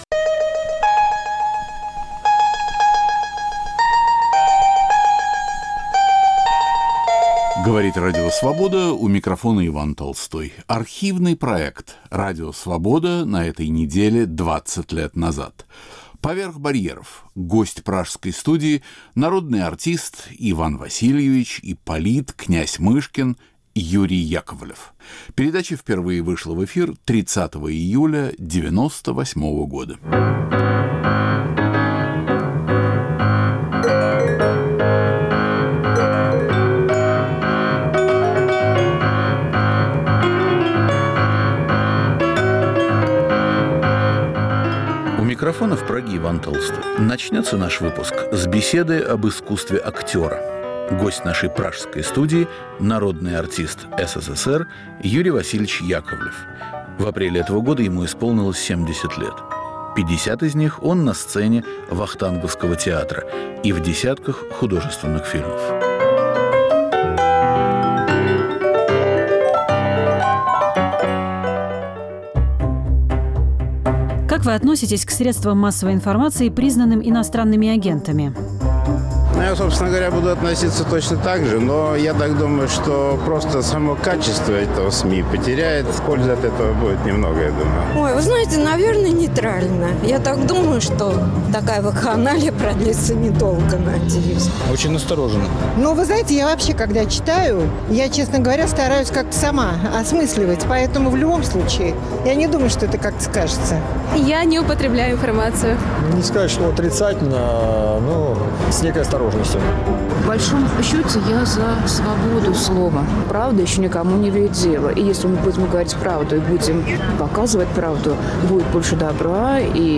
Радио Свобода на этой неделе 20 лет назад: Гость в студии актер Юрий Яковлев
Архивный проект. Иван Толстой выбирает из нашего эфира по-прежнему актуальное и оказавшееся вечным.